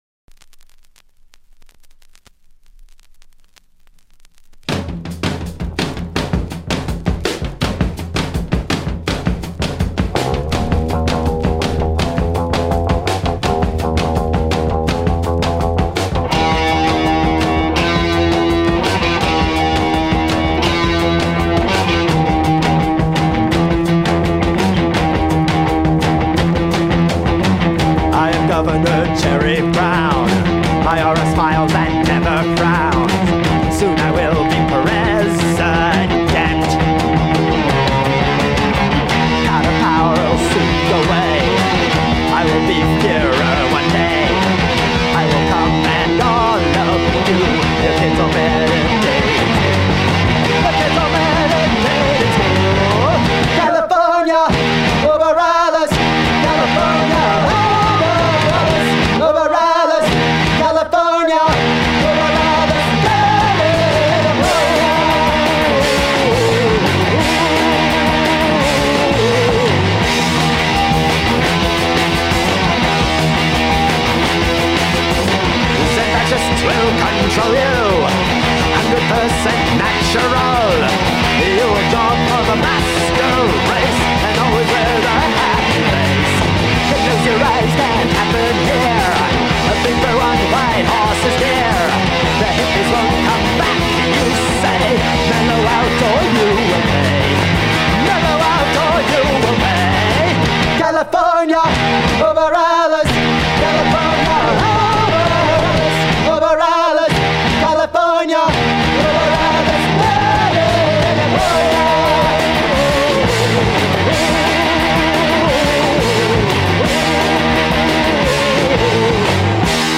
original vinyl 45 rpm